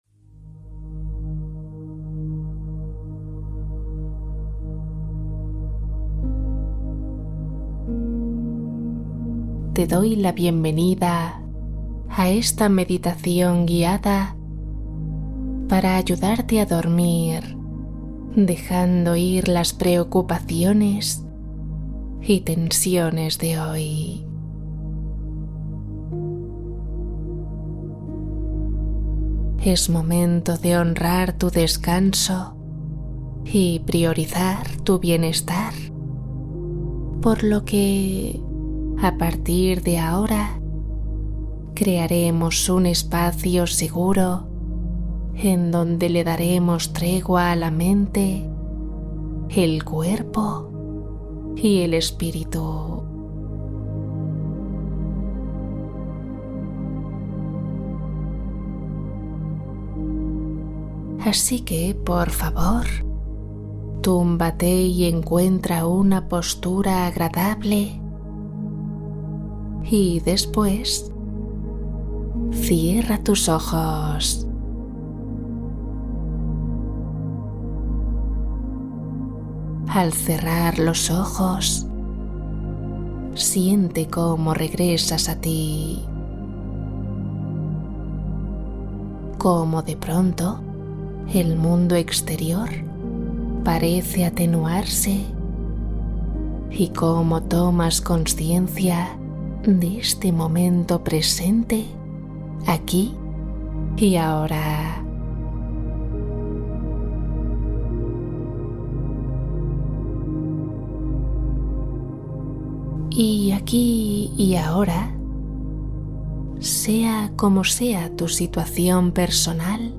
Duerme sin preocupaciones Meditación guiada para descansar toda la noche